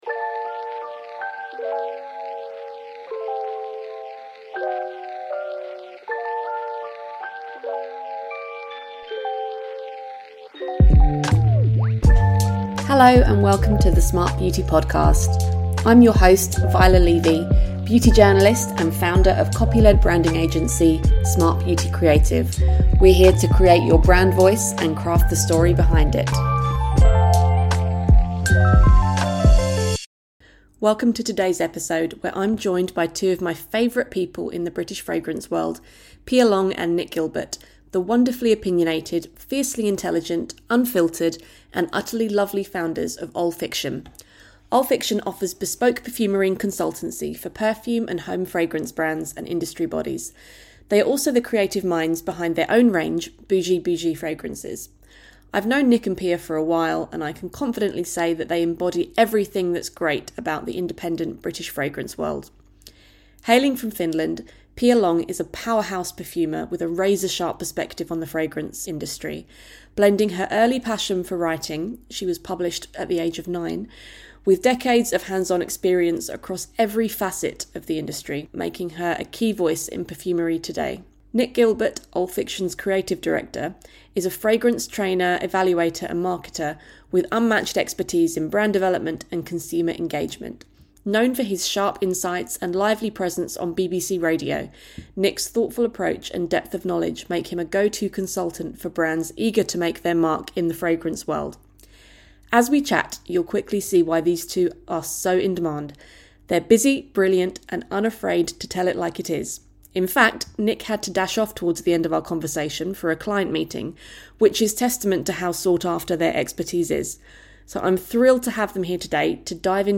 I hope you enjoy the conversation.